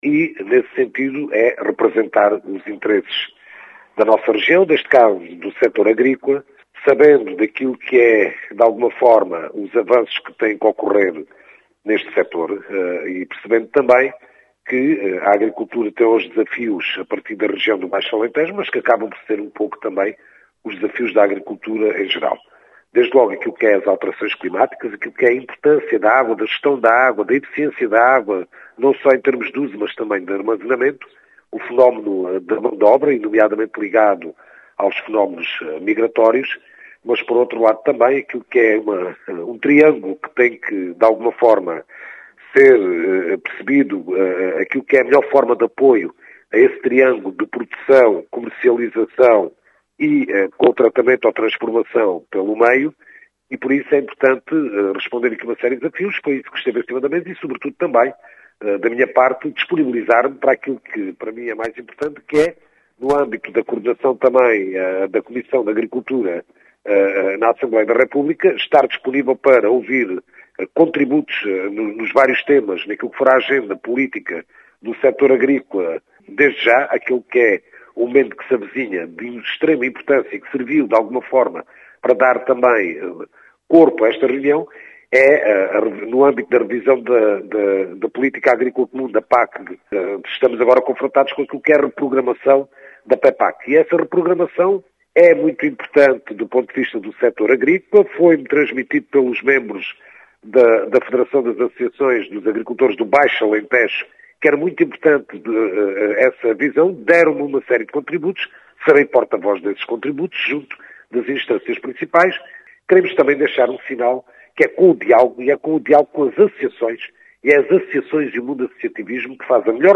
Em declarações à Rádio Vidigueira, o deputado do PS Nélson Brito, realçou a importância do “diálogo com o mundo do associativismo”, e afirma que vai ser “porta-voz” do sector agrícola.